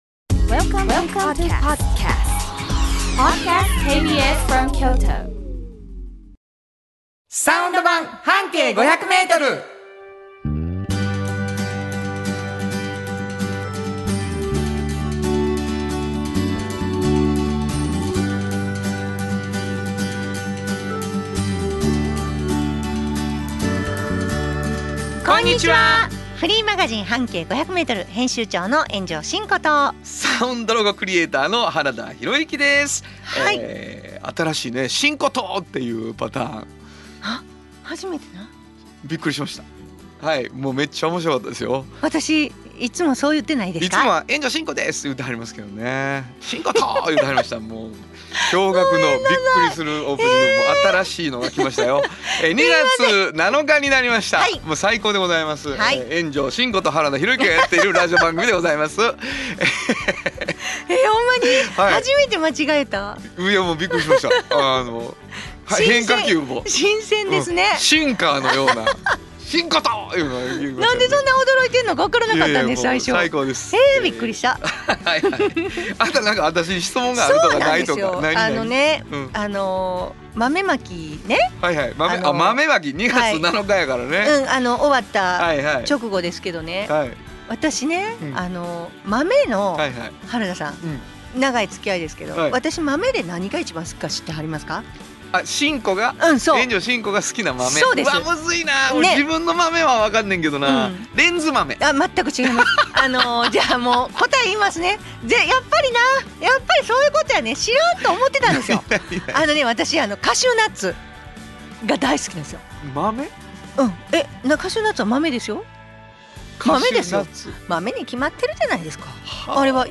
懐かしい曲を歌いました！